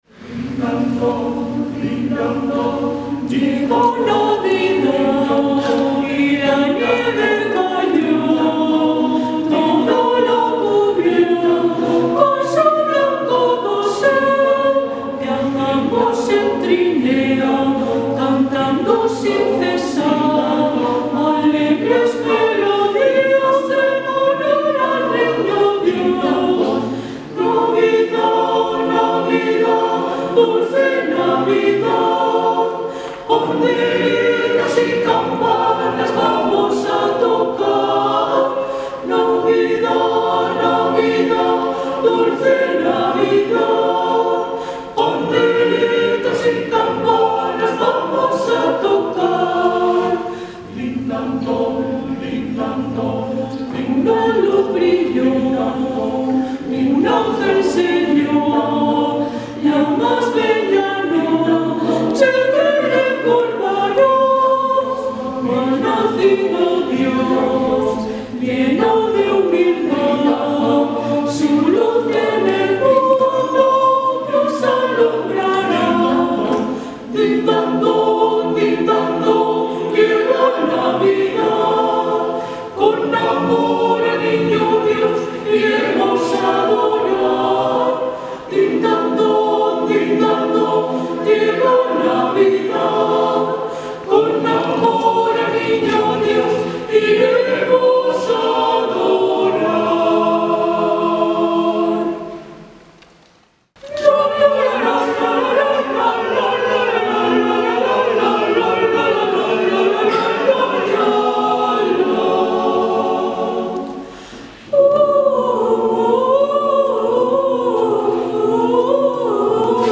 Misa del Gallo y Misa de Navidad 2014